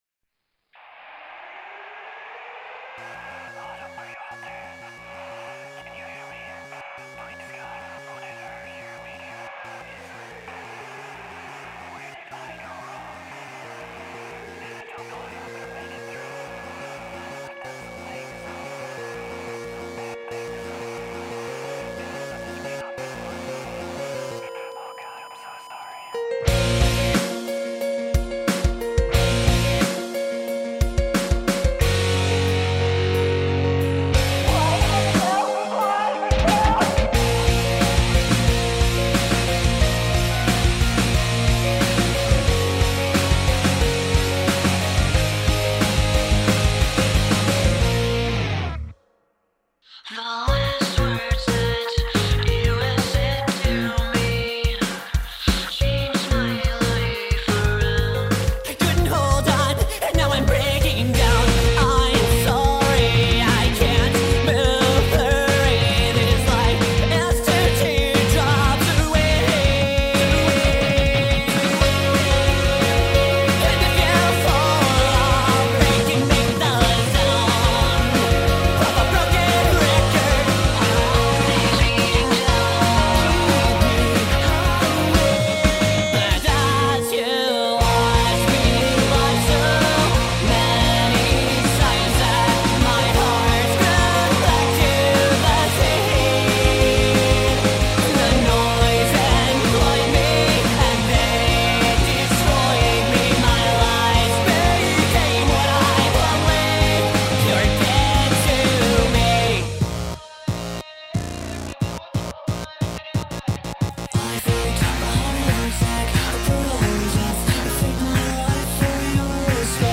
hi. im kinda new to recording somewhat and this is one of my first real mixes ive been trying to do. it consists of 2 guitars, bass, 2 vocalists, piano and synth.
me myself wasnt to sure on the volume of the piano as its hard to tell with this much going on but i feel its at a good volume. the crashes and hi hat i toned down a bit as i hear that the lower their volume in the mix for the master the better and more booming sound you will get.
i tried it on multiple systems and can hear it but the volume is inconsistent throughout the track even after compression.